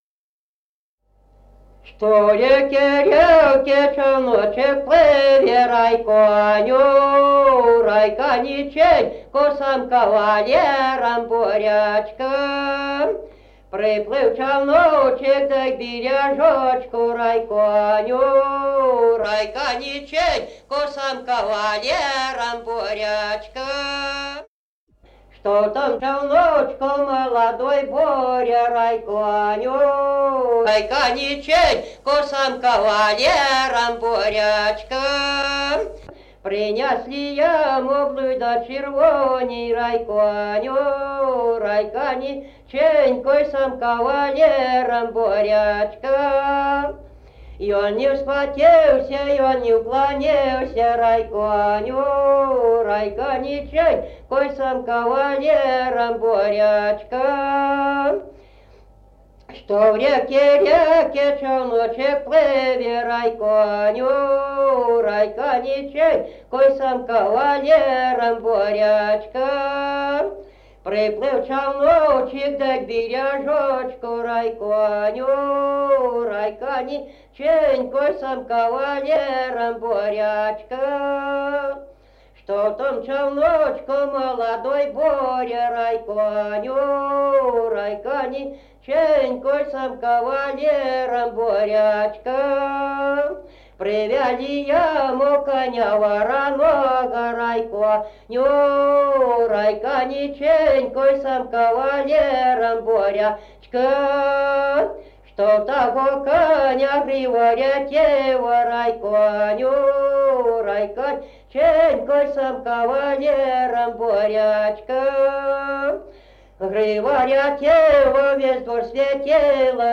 Народные песни Стародубского района «Что в реке, реке», новогодняя щедровная.